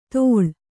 ♪ tōḷ